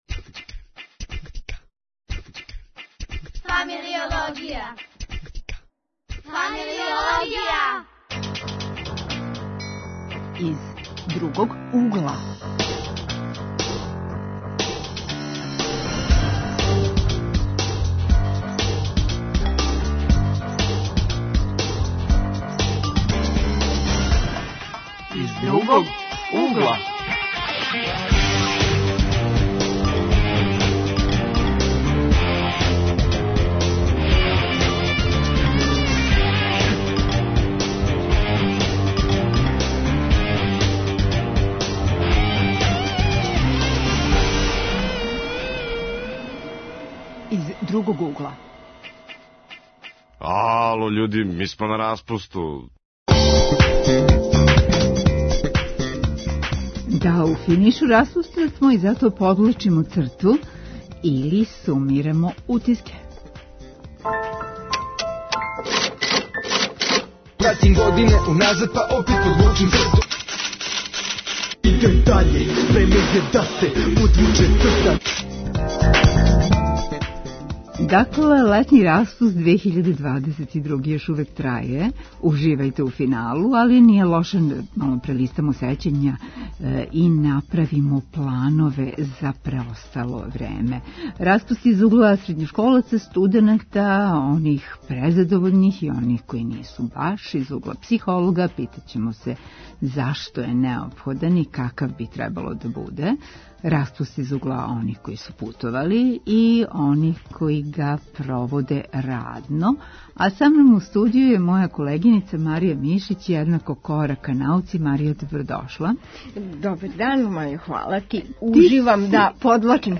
Гости у студију су студенти.